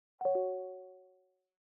call-leave.ogg